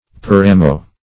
Search Result for " paramo" : The Collaborative International Dictionary of English v.0.48: Paramo \Pa"ra*mo\, n.; pl.